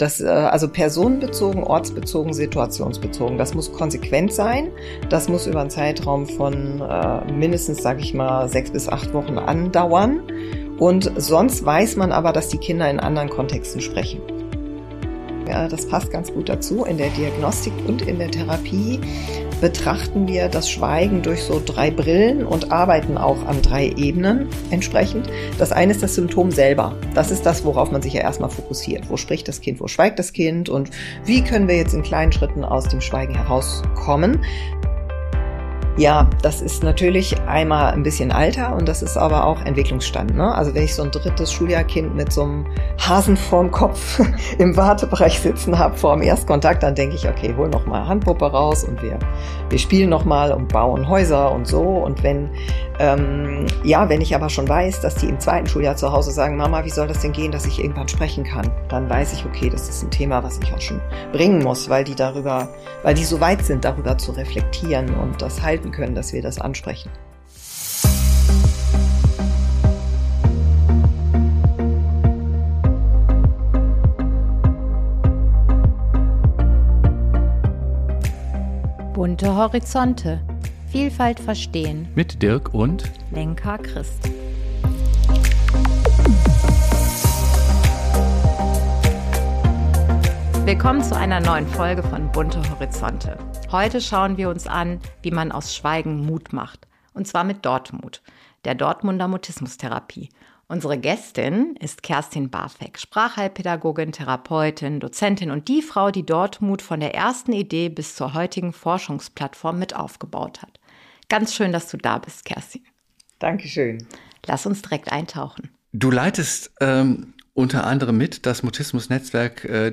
Ein Gespräch über Therapie mit Haltung, mutige Kinder und den langen Atem, den echte Teilhabe manchmal braucht.